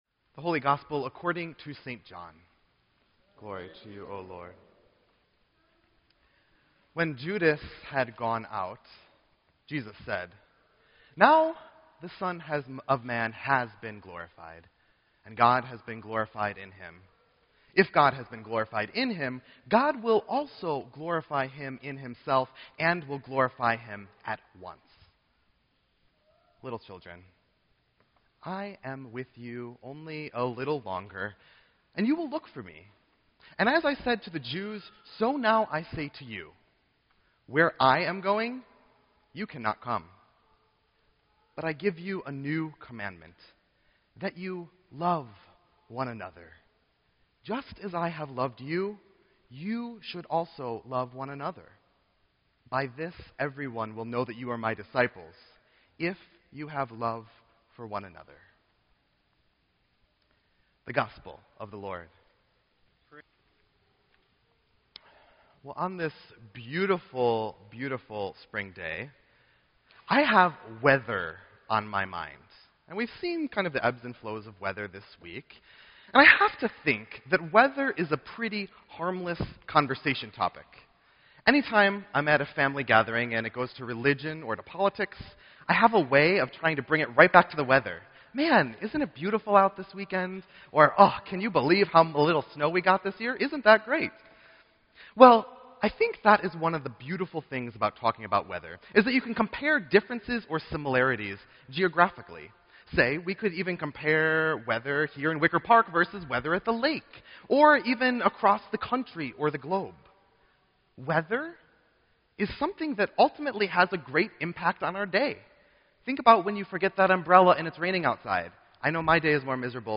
Sermon_4_24_16.mp3